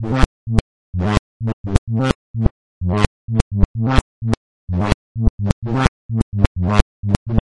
基调舞a2 f2 128 bpm fizzy bass 2
Tag: 最小 狂野 房屋 科技 配音步 贝斯 精神恍惚 舞蹈 俱乐部